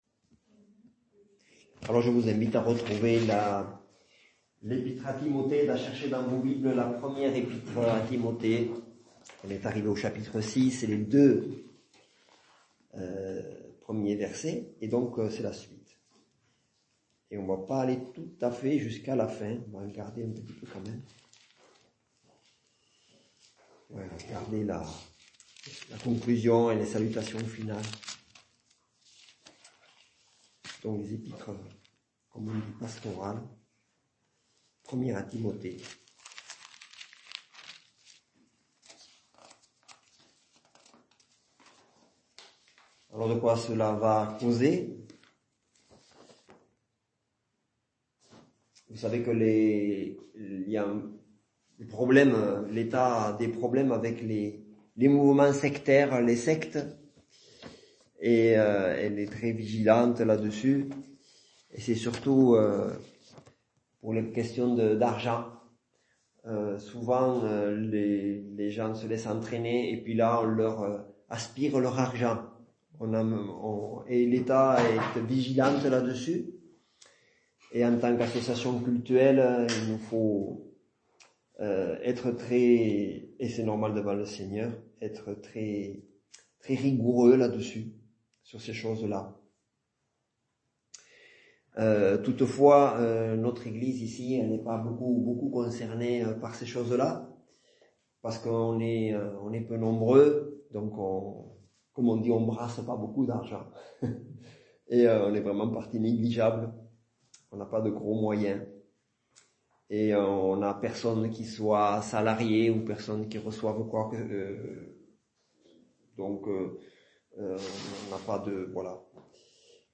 Culte du dimanche 14 juillet 2024 - EPEF